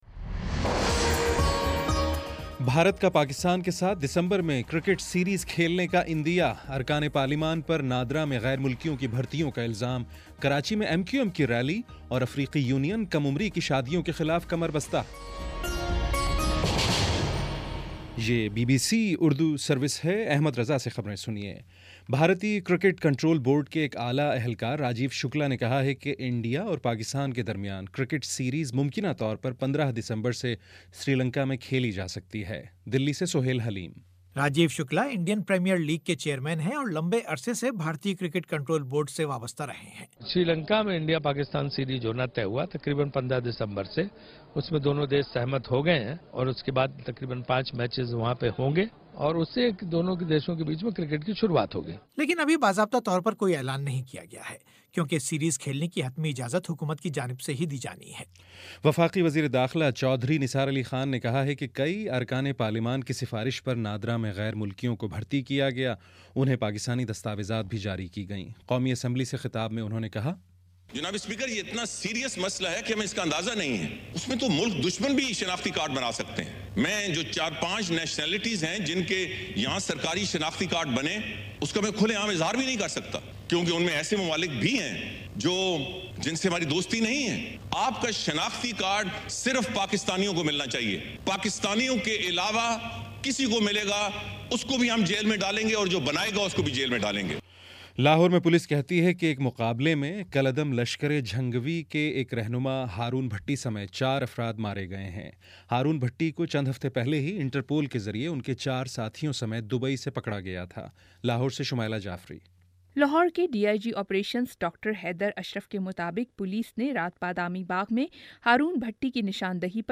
نومبر 26 : شام پانچ بجے کا نیوز بُلیٹن